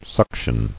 [suc·tion]